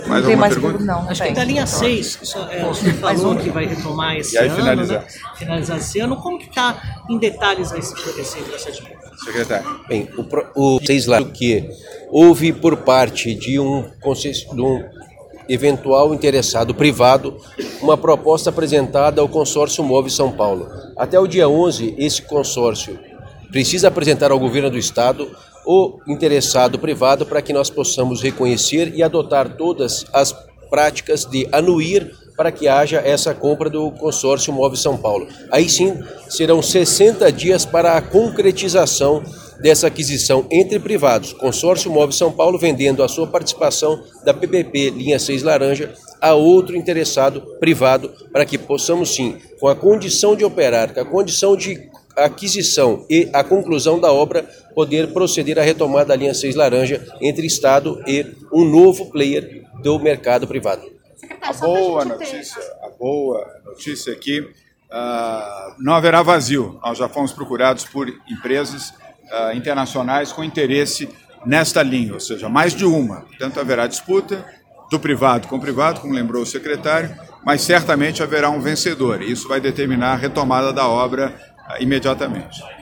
doria-e-baldy-linha-6.mp3